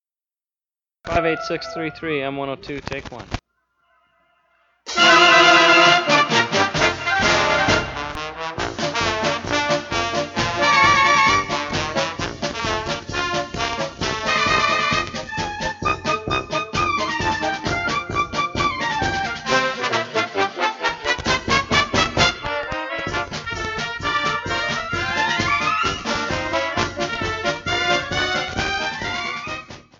UPC: Soundtrack